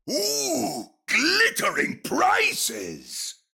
I was waiting for this voice line to pop up.